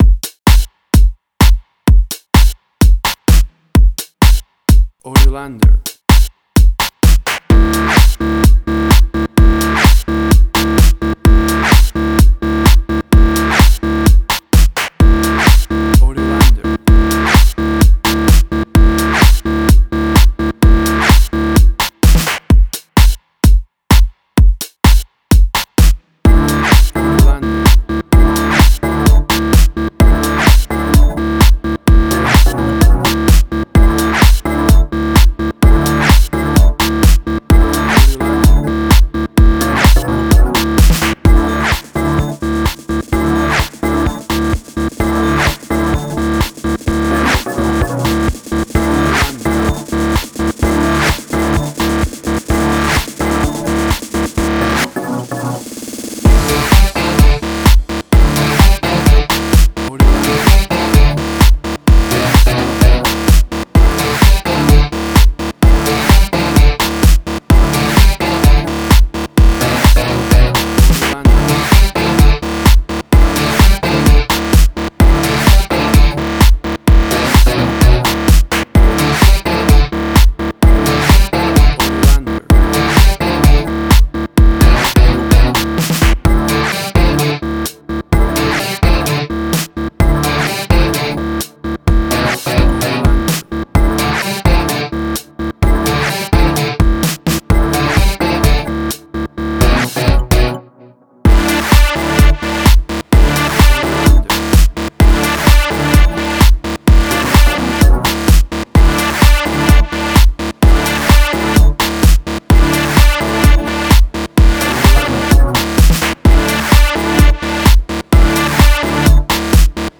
House.
Tempo (BPM): 128